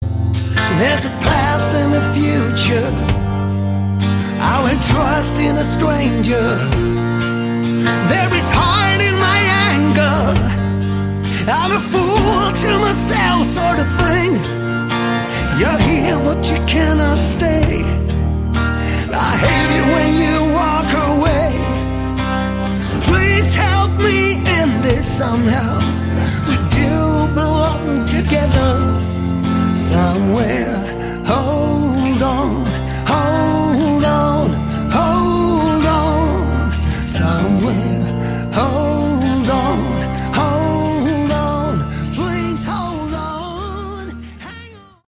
vocals
bass
guitar
drums